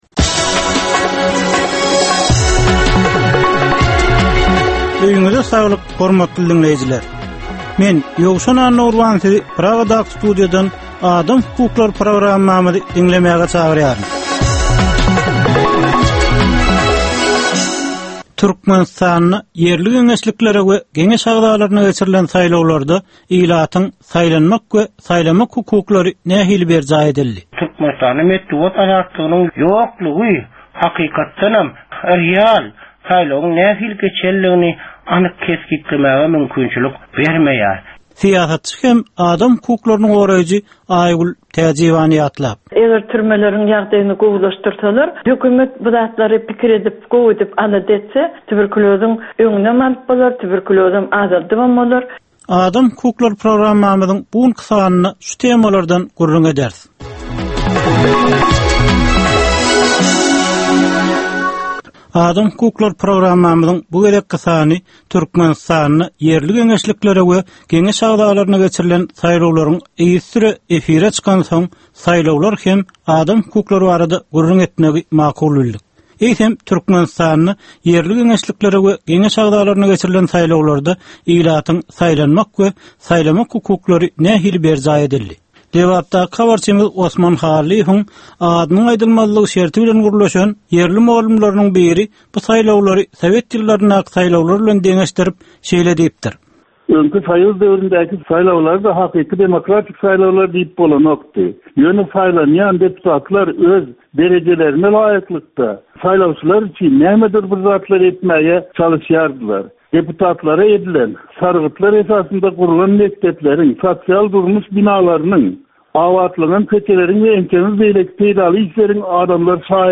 Türkmenistandaky ynsan hukuklarynyn meseleleri barada 15 minutlyk ýörite programma. Bu programmada ynsan hukuklary bilen baglanysykly anyk meselelere, problemalara, hadysalara we wakalara syn berilýar, söhbetdeslikler we diskussiýalar gurnalýar.